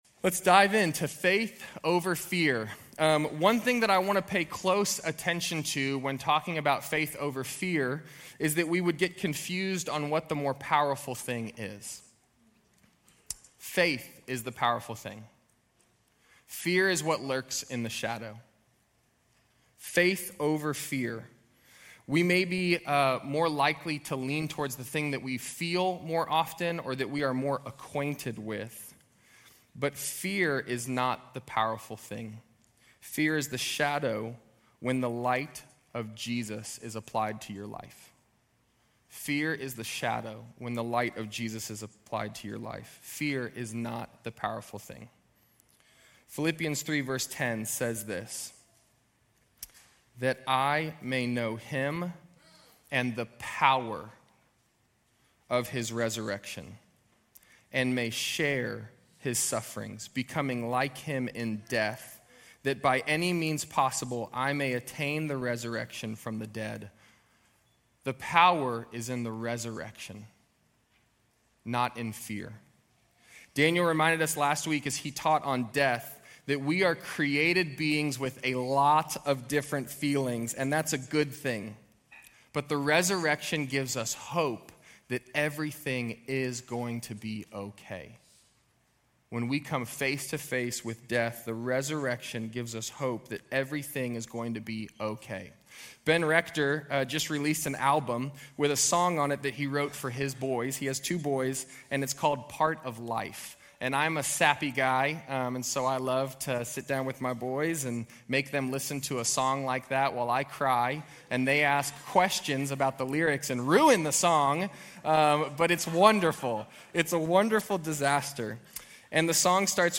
Grace Community Church University Blvd Campus Sermons 6_11 University Blvd Campus Jun 02 2025 | 00:30:09 1x 00:00 / 00:30:09 Subscribe Share RSS Feed Share Link Embed